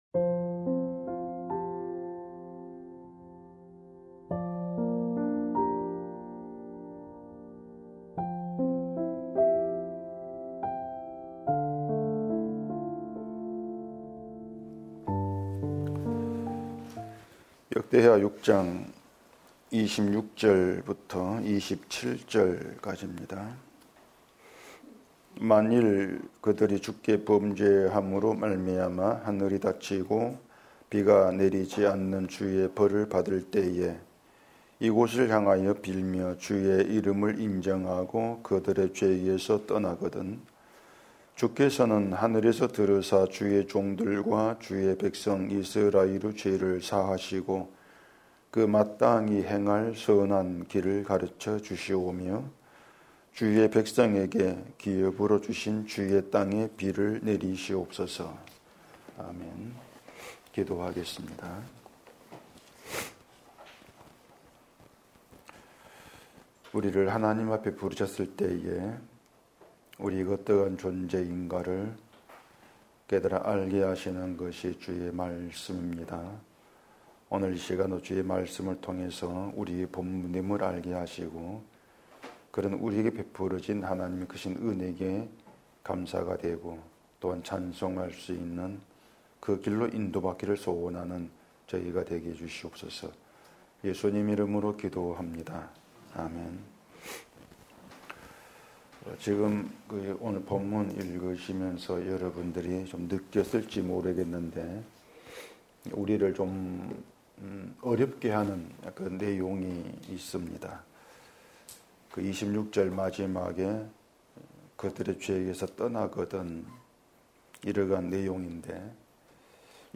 주일오전 - (26강) 죄에서 떠나거든